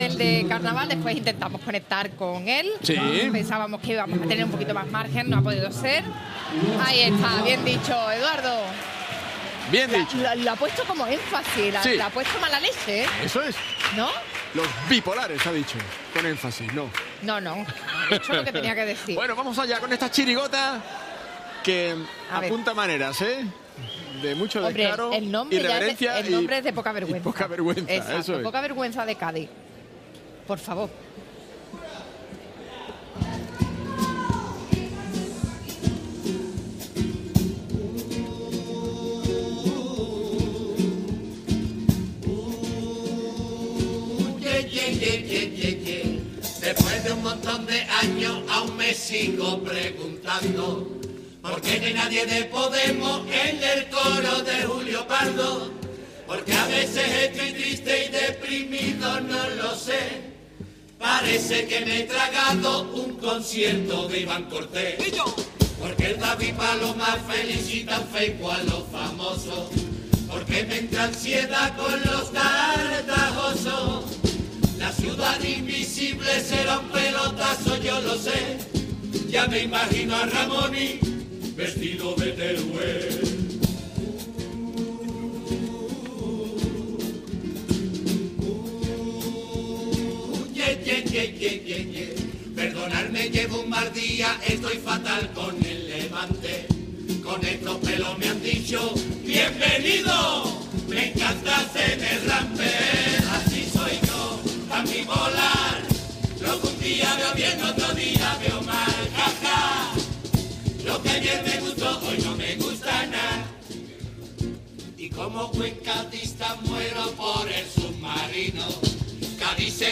del COAC Carnaval de Cádiz 2023